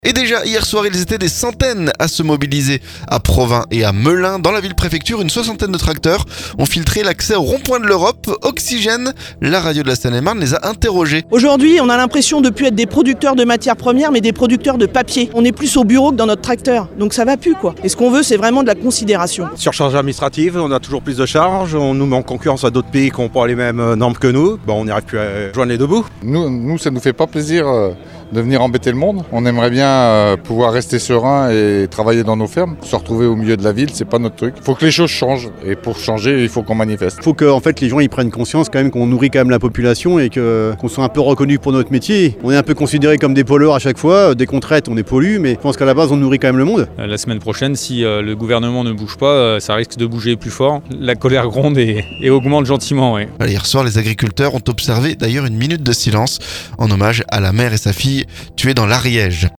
AGRICULTEURS "Surcharge administrative, concurrence déloyale..." On a interrogé les manifestants à Melun
Jeudi soir, les agriculteurs étaient des centaines à se mobiliers à Provins et Melun. Dans la ville préfecture, une soixantaine de tracteurs ont filtré l'accès au rond point de l'Europe... Oxygène, la radio de la Seine-et-Marne, les a interrogés.